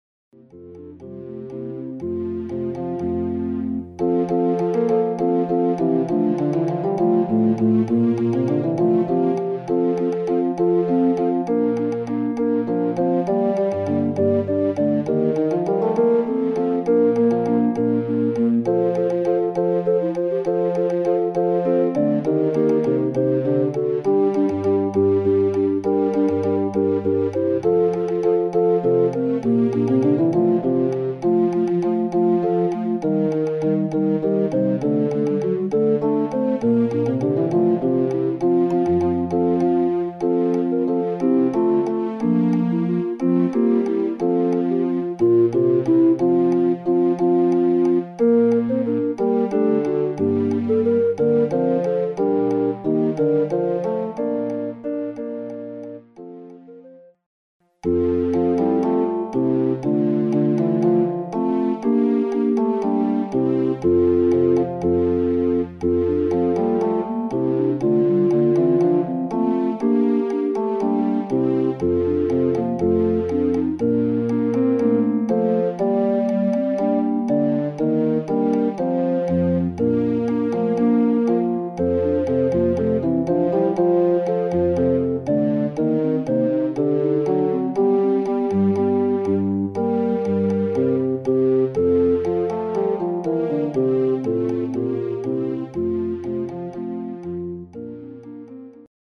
arr. for Recorders, Bass and perc.
Quintet, AATBB, $10.-